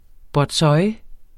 Udtale [ bɒdˈsɒj ]